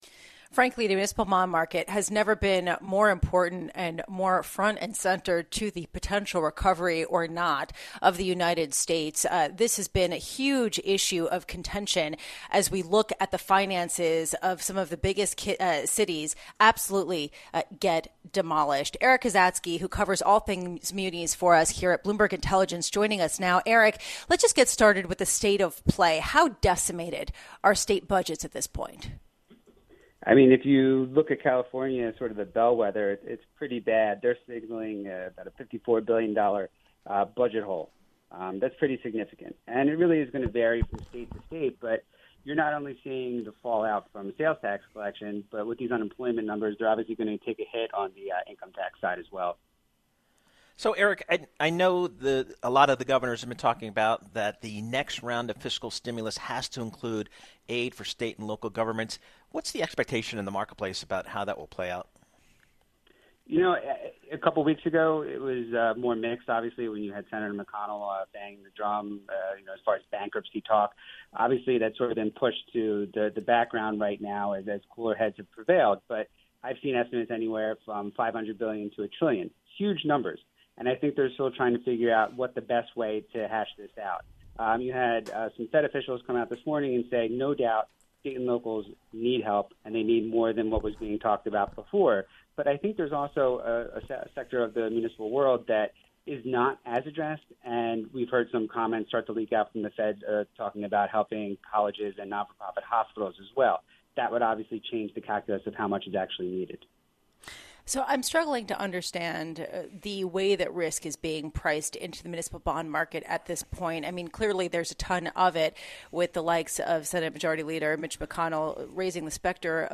Muni Yields Falling As $120 Billion Debt Set To Mature (Radio)